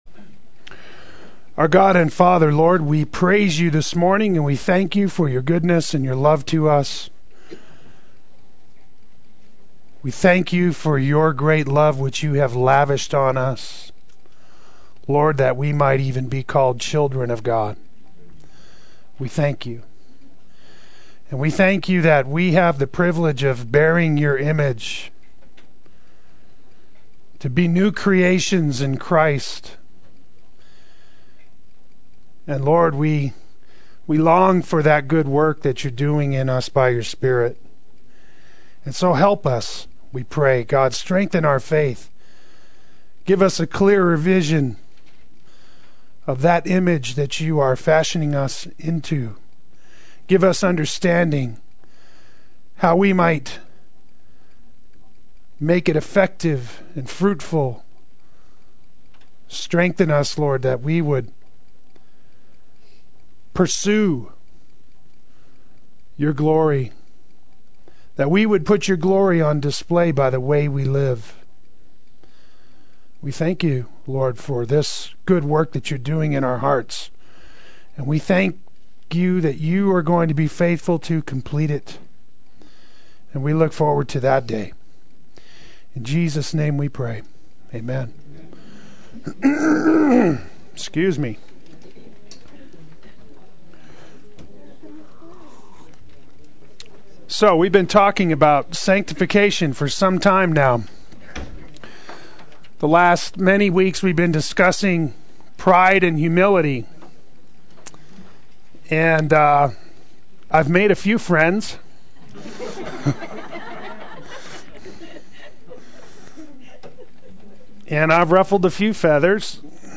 Play Sermon Get HCF Teaching Automatically.
Humility and Self-Denial and Following Christ Adult Sunday School